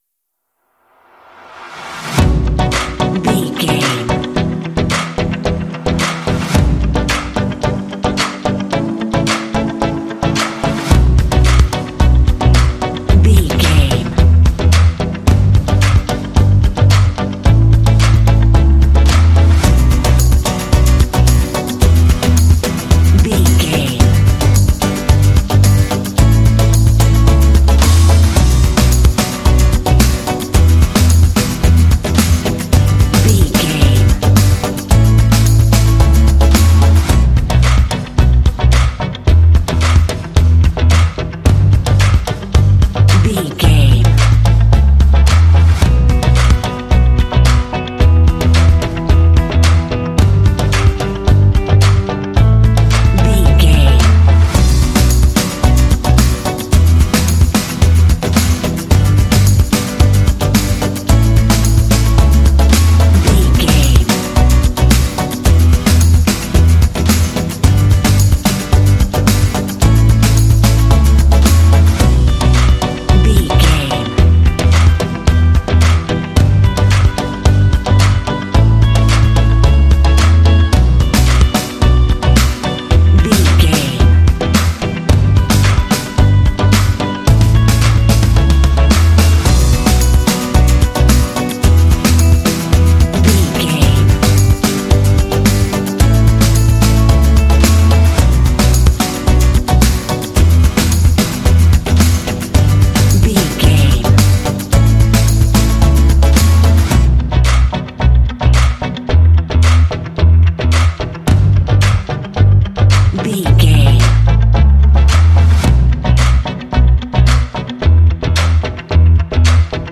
Aeolian/Minor
cool
energetic
motivational
dramatic
synthesiser
drums
acoustic guitar
bass guitar
electric guitar
rock
alternative rock
indie